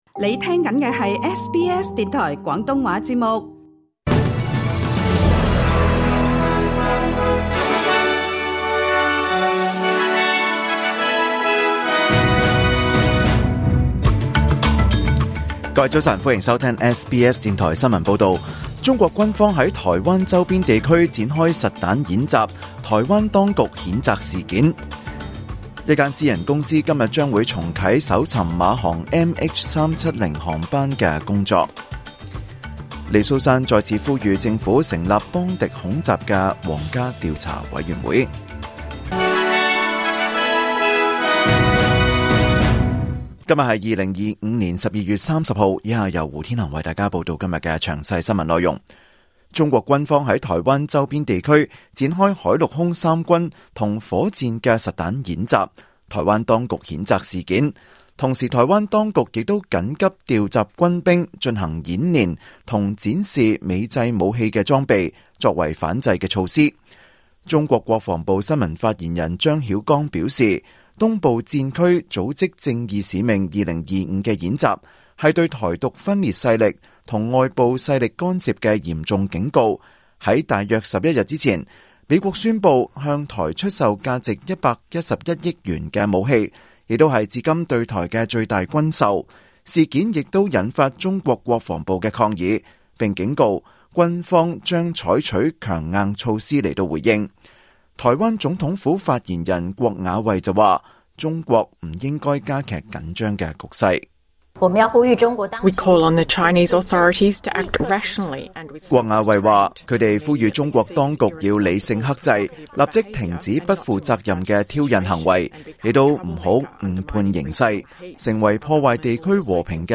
2025年12月30日 SBS 廣東話節目九點半新聞報道。